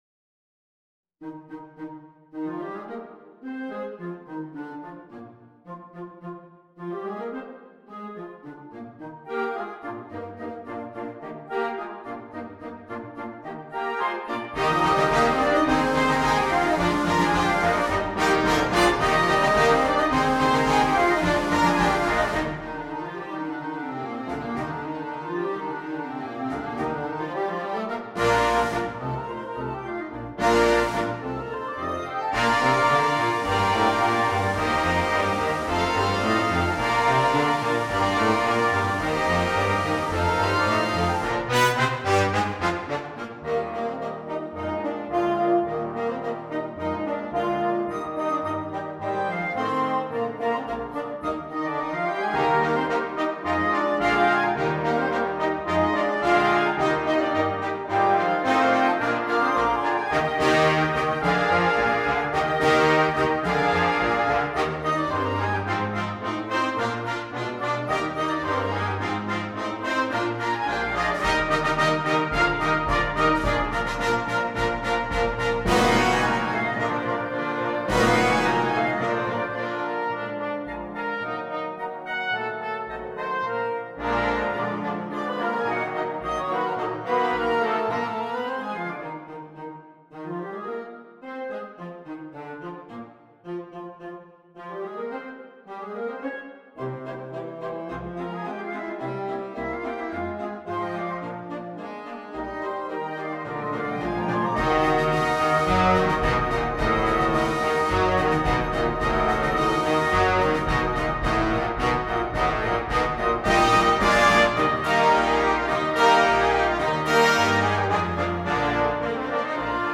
Wind Band
A delightful overture full of charm and rhythmic vitality!